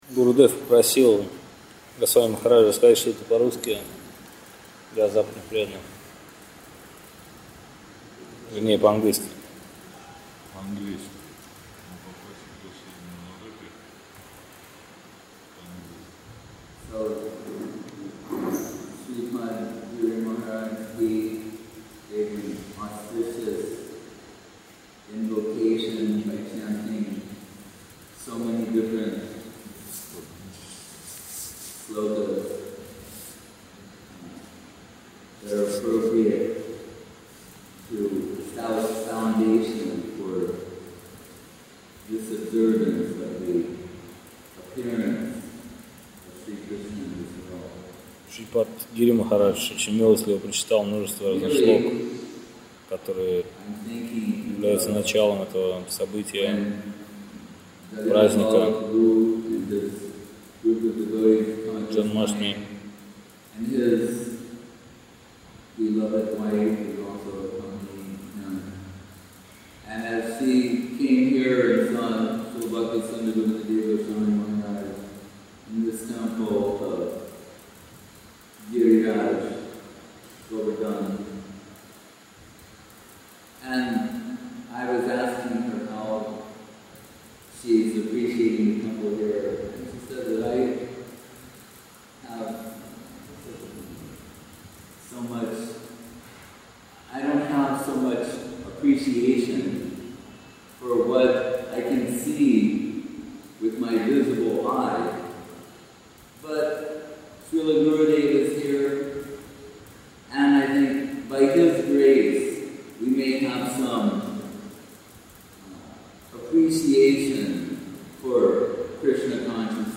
Place: Srila Sridhar Swami Seva Ashram Govardhan